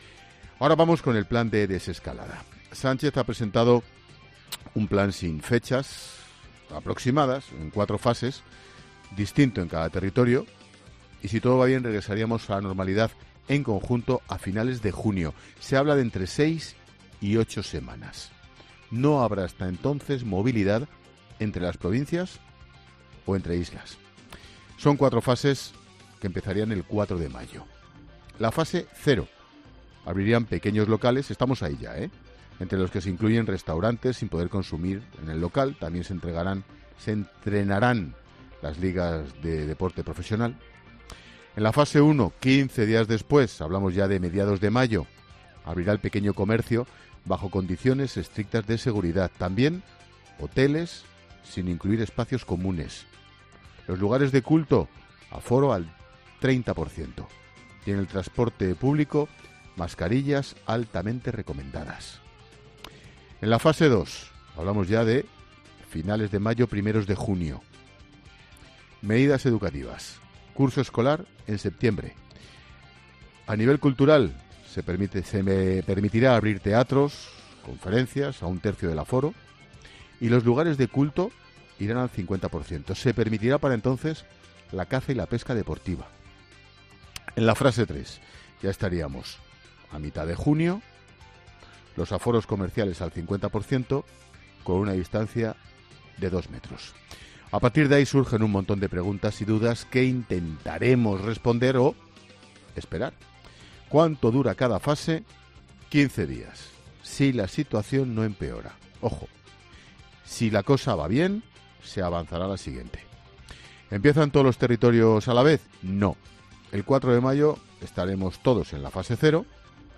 El presentador de 'La Linterna' responde a todas las preguntas que el plan anunciado por Sánchez ha generado en la ciudadanía